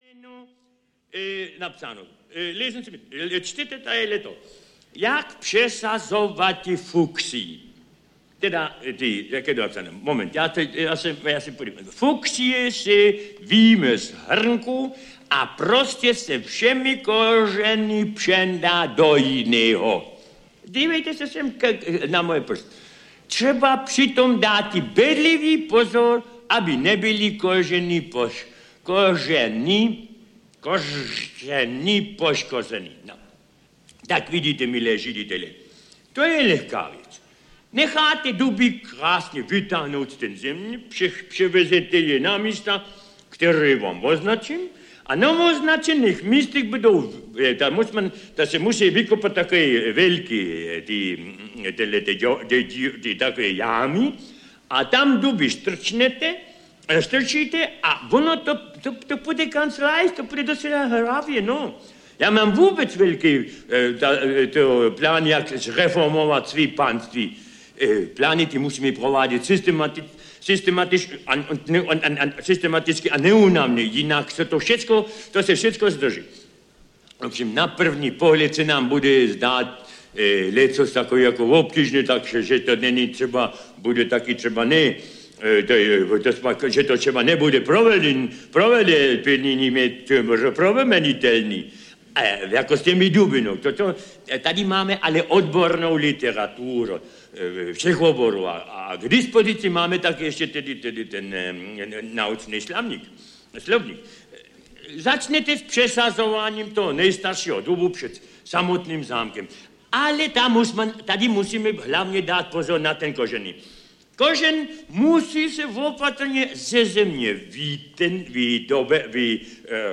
Audiokniha Supraphon vypravuje...2 - příběhy jejichž autory jsou Němec, Hašek, Neruda, Čapek, Haas, Rada - soubor mluveného slova jehož autory jsou klasici české literatury.
Ukázka z knihy
• InterpretVlasta Burian, Hugo Haas, Jan Pivec, Vlastimil Rada, Dana Medřická, Václav Vydra st.